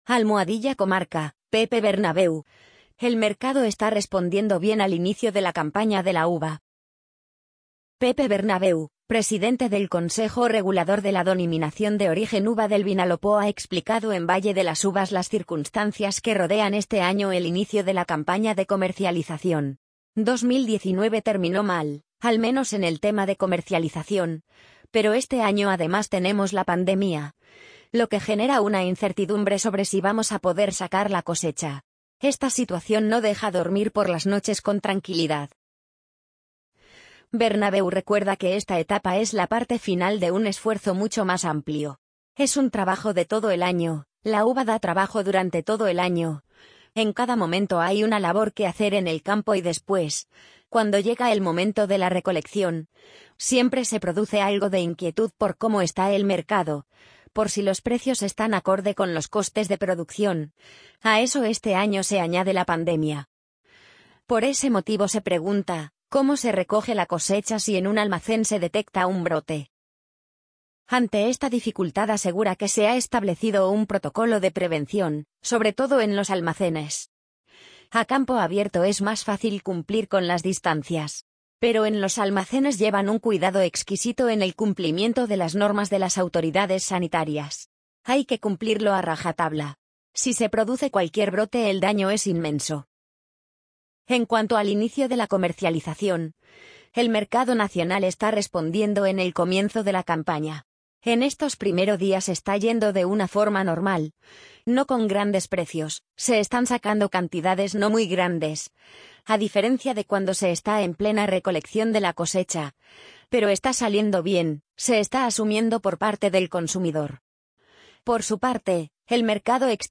amazon_polly_44737.mp3